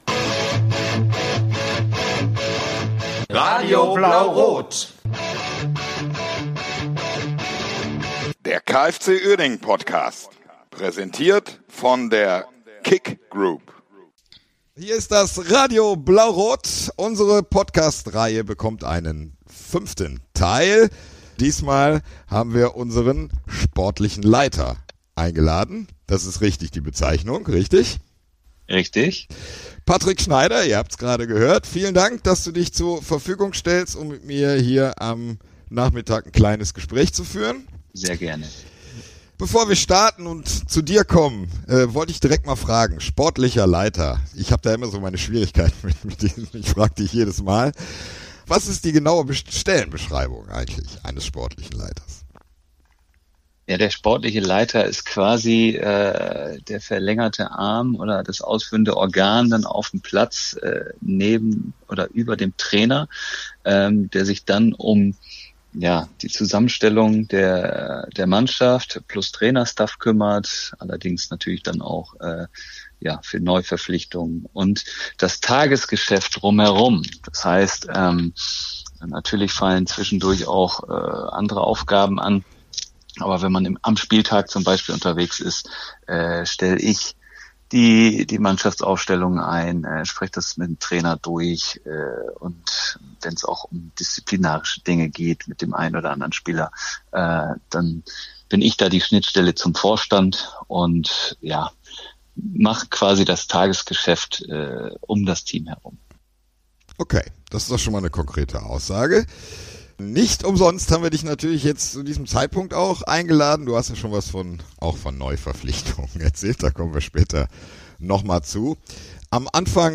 Im sehr informativen Gespräch